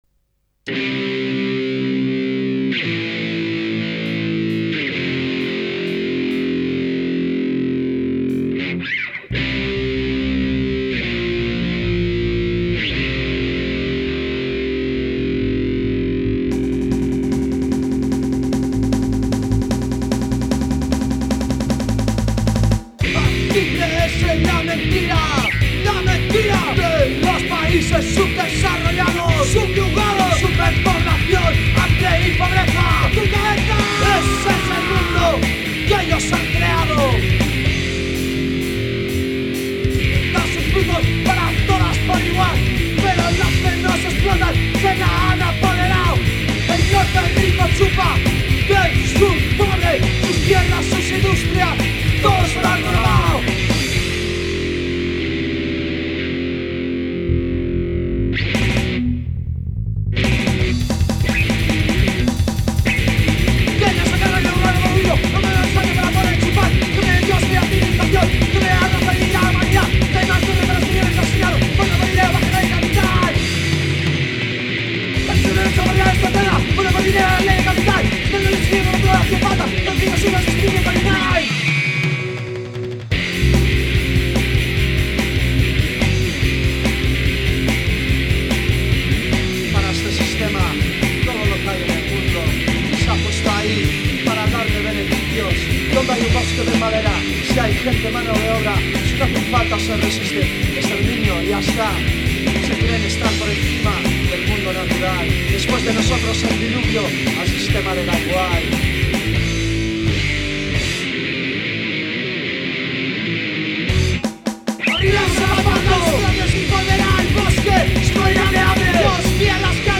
voz y guitarra
batería
punk